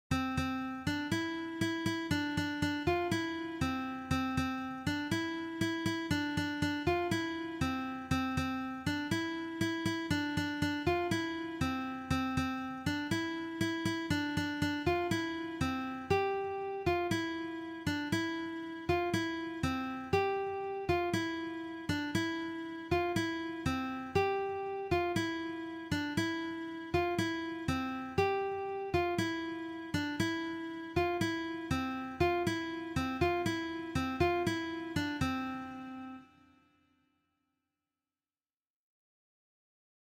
Gitarre, akustische Gitarre